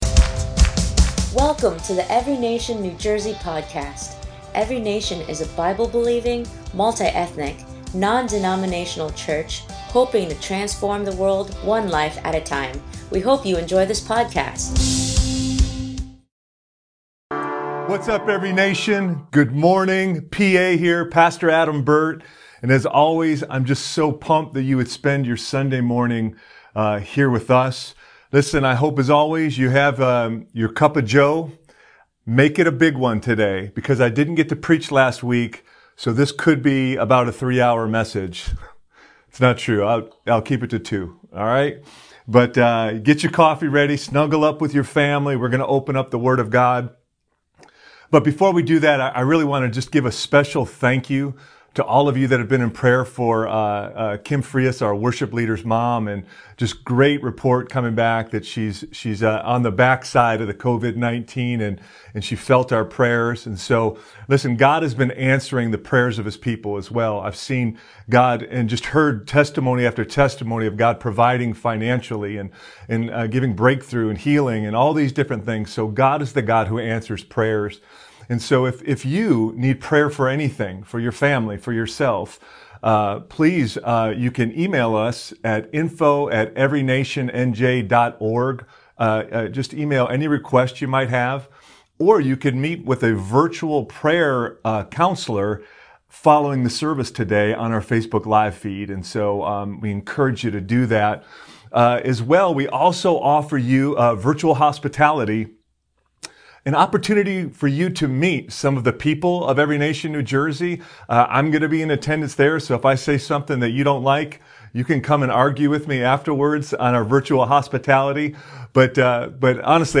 ENCNJ SERMON 5/17/20